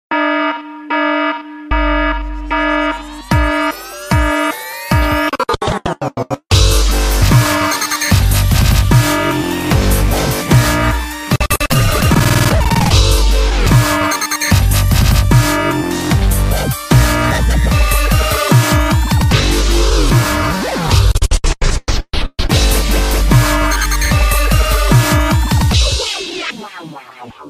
Dubstep Alarm.mp3